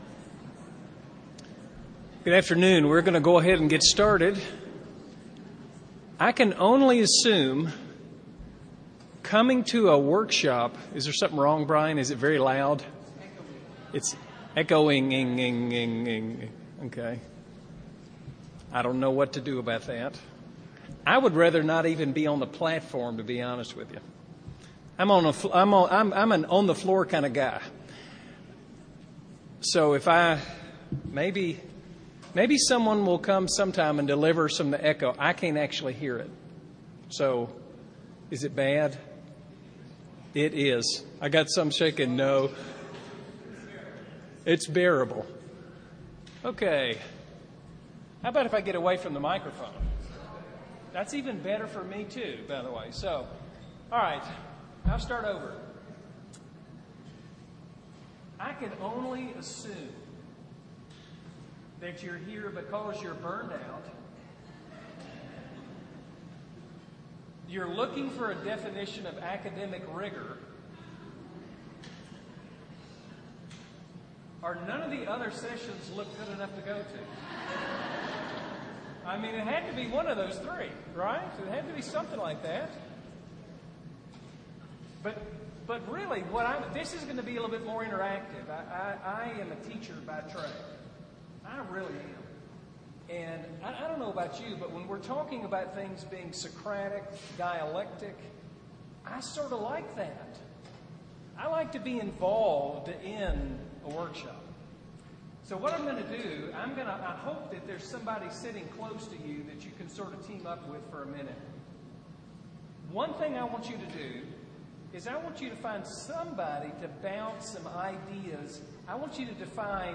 2008 Workshop Talk | 0:57:19 | All Grade Levels, Leadership & Strategic, General Classroom